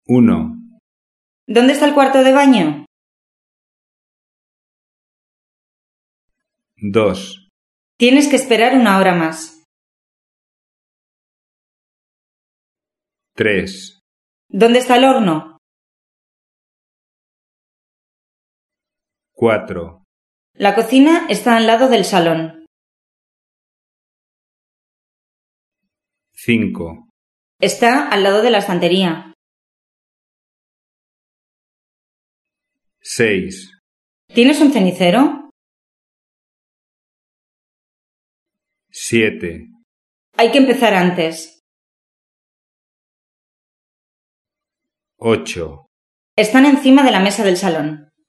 En castellano, muchas palabras se unen en la lengua hablada. Por ejemplo: va a escuchar se dice normalmente: /vaescuchar/